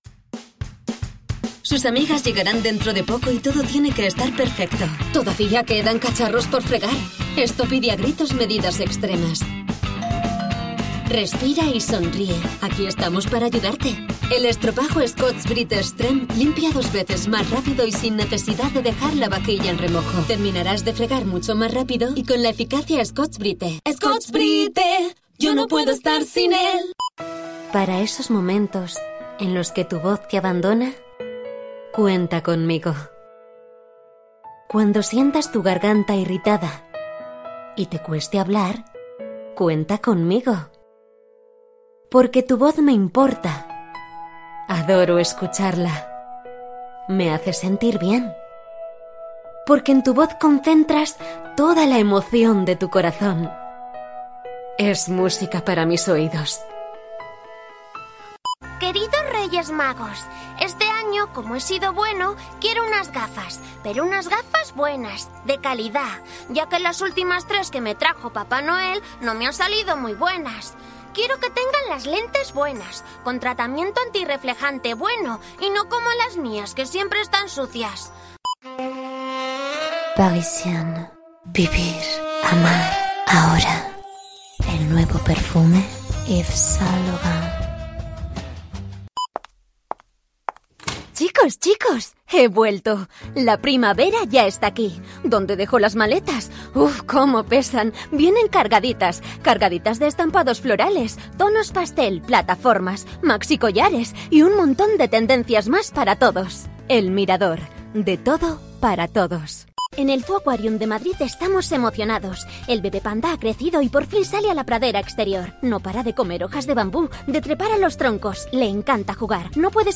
ACTRIZ DOBLAJE / LOCUTORA PUBLICITARIA / CANTANTE Voz versátil, cálida, elegante, sensual, fresca, natural, dulce, enérgica...
kastilisch
Sprechprobe: Werbung (Muttersprache):
A versatile, experienced , clear, credible and vibrant voice over artist.